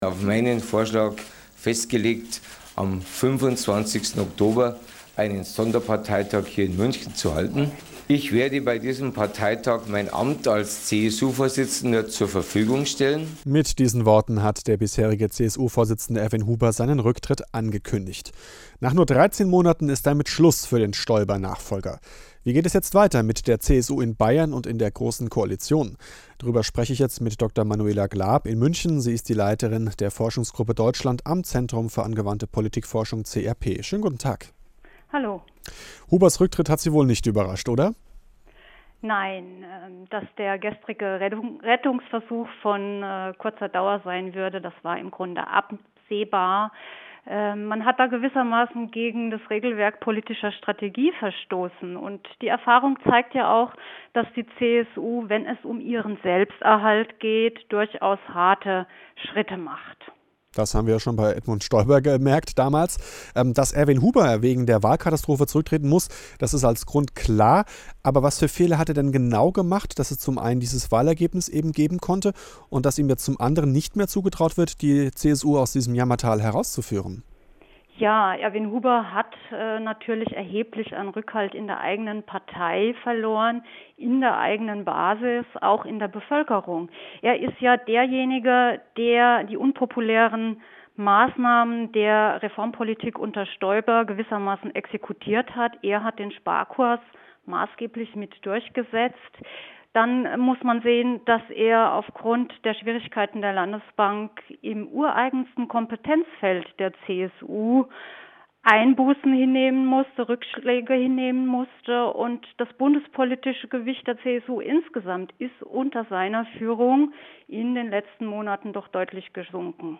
Audio-Interview